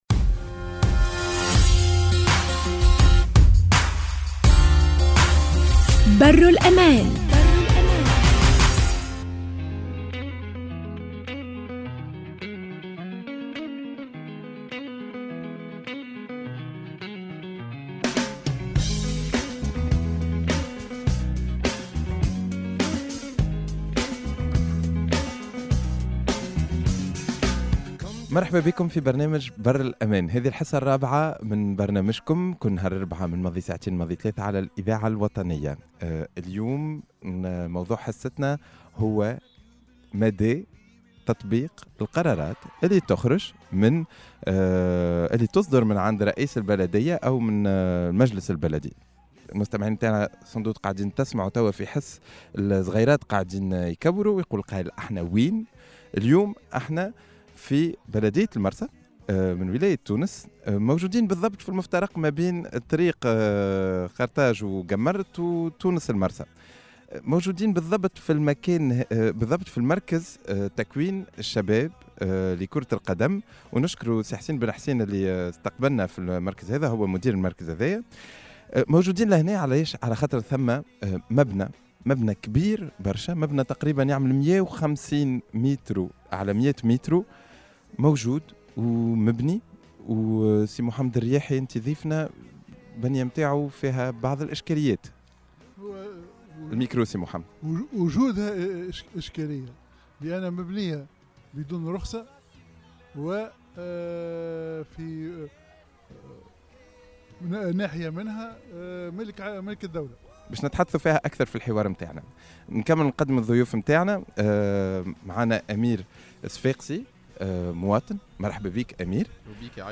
(enregistrée sur le toit d’un bâtiment municipal à La Marsa) La décentralisation et le pouvoir local sont définis dans le chapitre 7 de la nouvelle constitution tunisienne. Avant d’aborder les bienfaits et inconvénients de la décentralisation, nous avons décrypté le fonctionnement des municipalités tunisiennes actuellement en vigueur (régi par la loi organiques des municipalités de 1975).